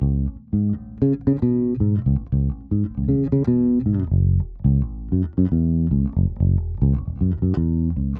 04 Bass PT1.wav